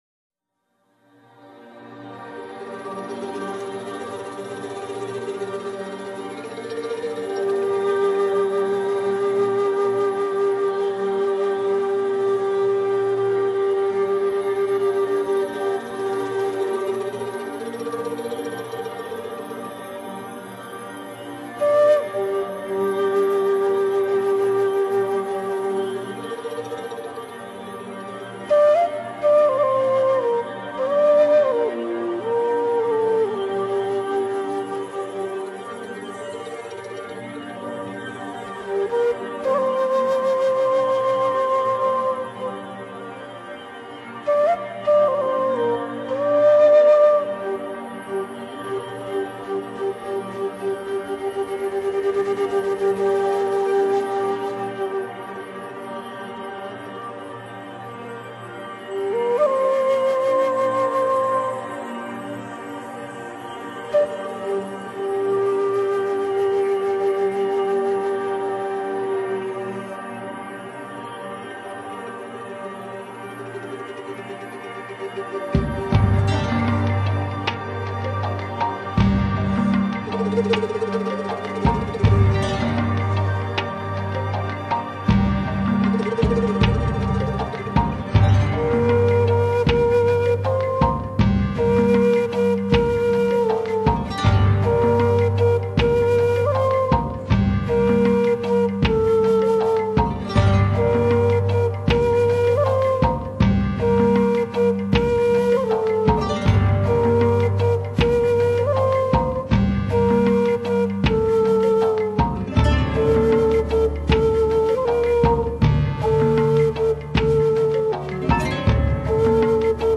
在美洲，声音优美的木笛早已俘获了一代又一代人的想象力。